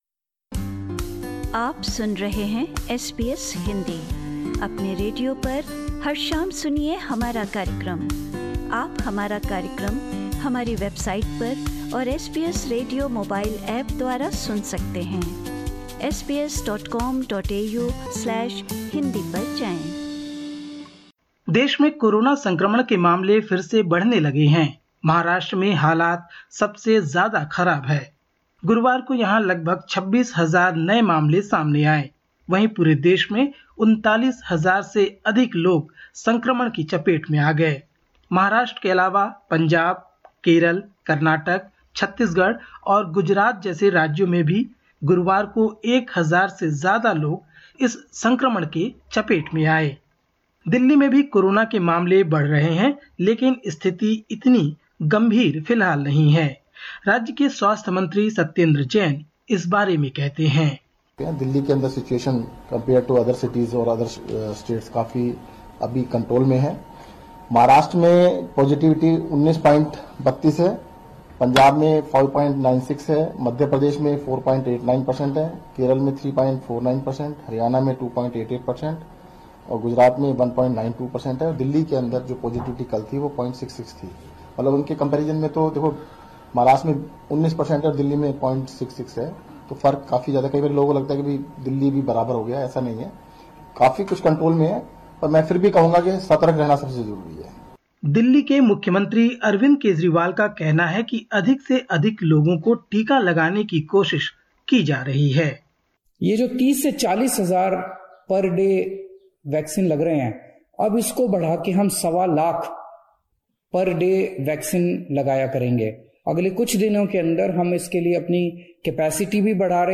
भारत की रिपोर्ट